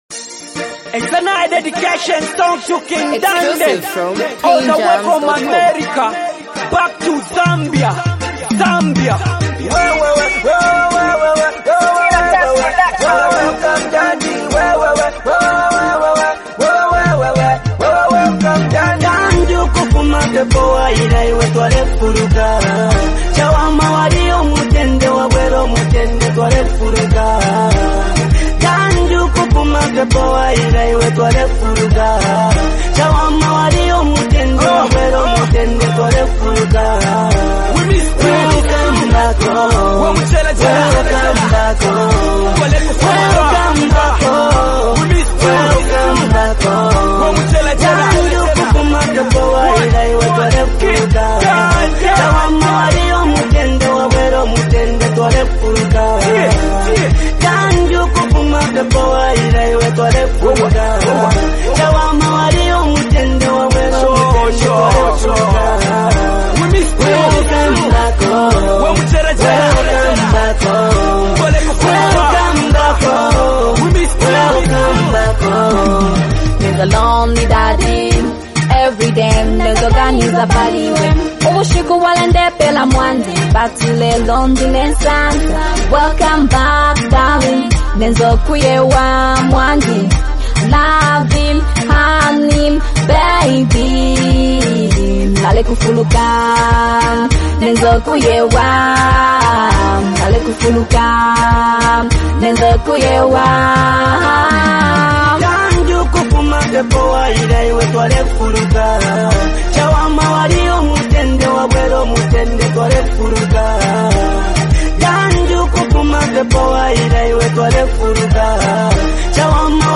The song carries a strong sense of unity and reverence